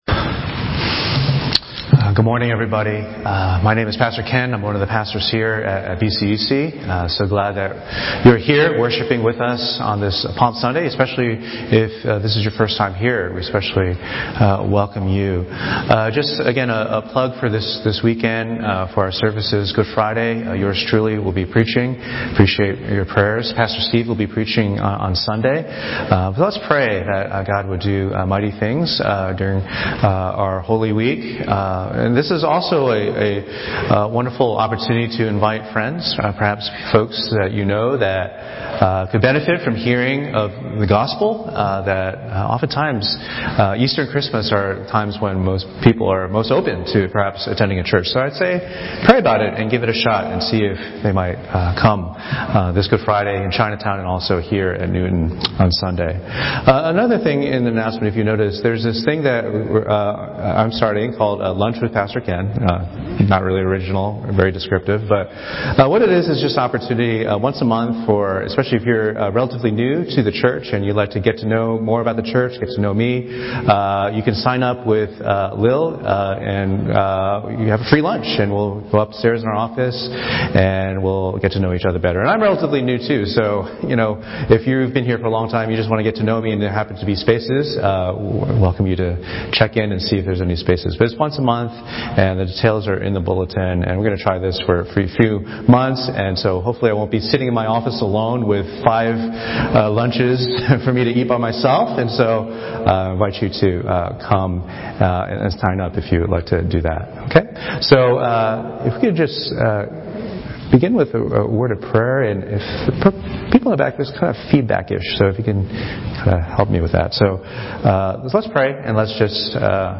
The umbrella term/category for all Sermons from all congregations.